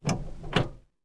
car-steer-2.ogg